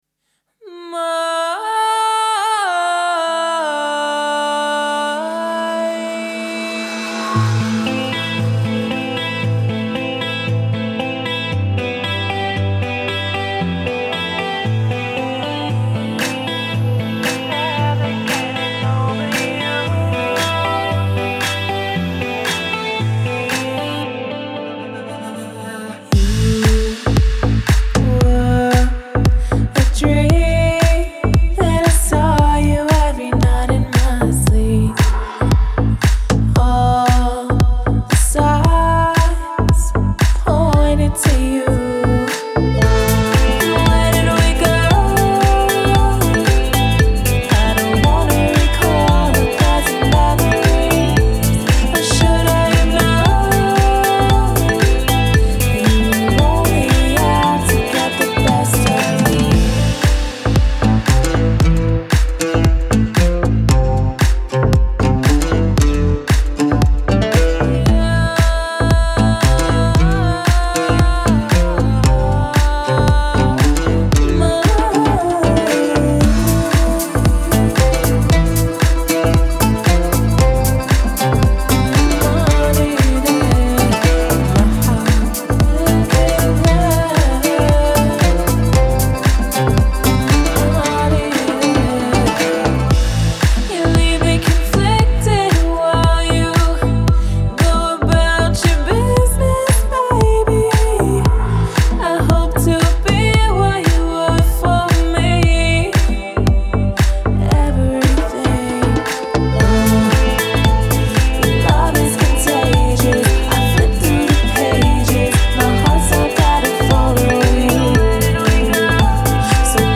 это трек в жанре прогрессивного хауса